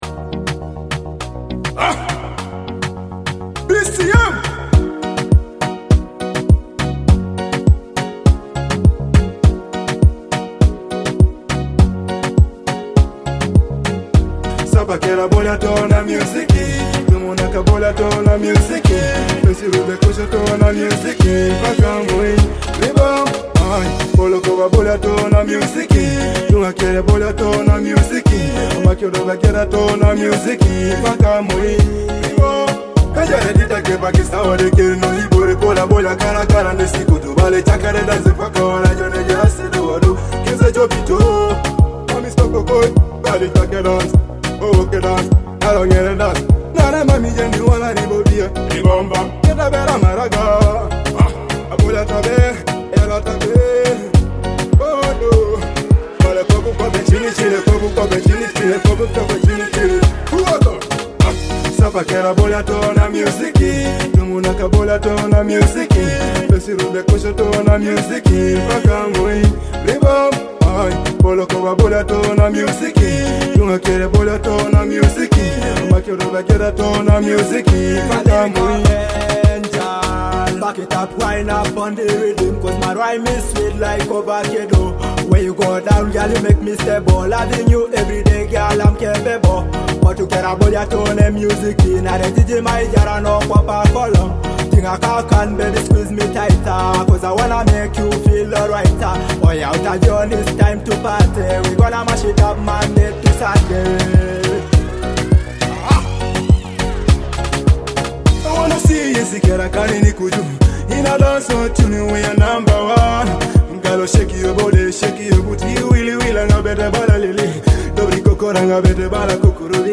a vibrant dancehall hit with infectious rhythms.